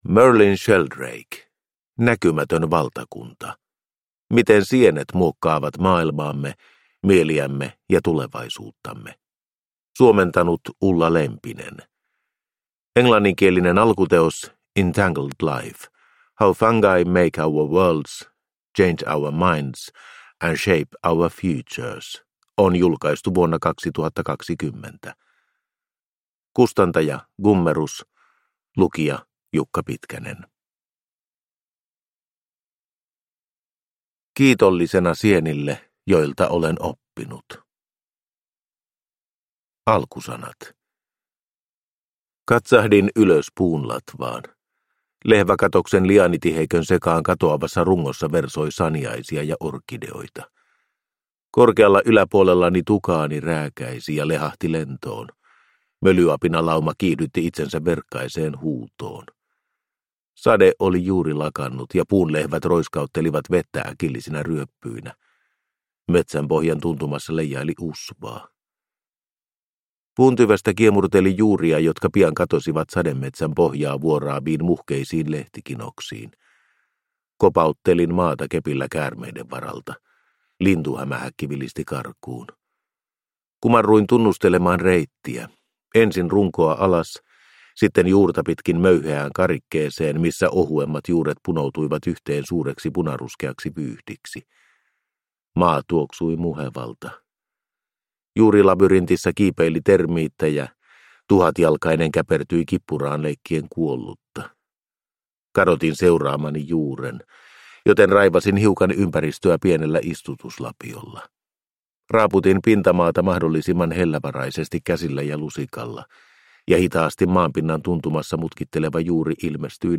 Näkymätön valtakunta – Ljudbok